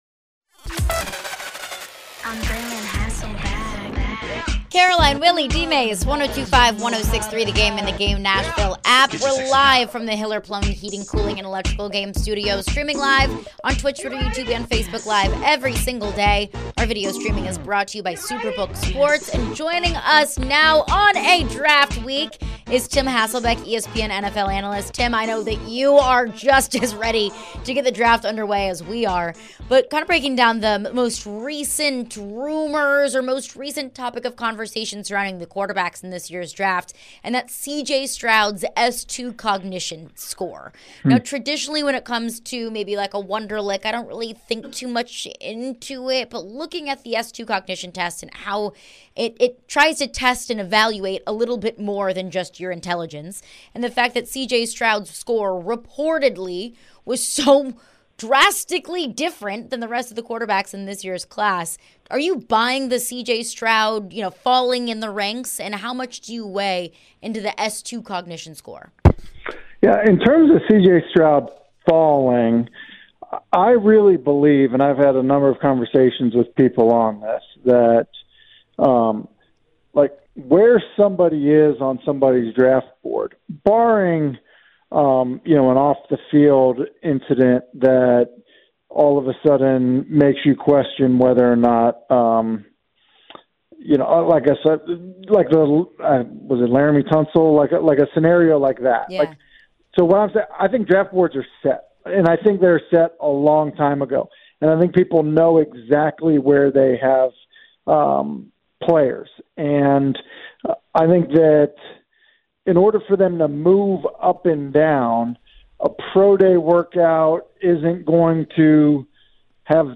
Tim Hasselbeck Interview (4-25-23)
ESPN NFL Analyst Tim Hasselbeck joins the show prior to the NFL Draft on Thursday for his weekly hit. What does he think of the S2 testing & what the Titans could do?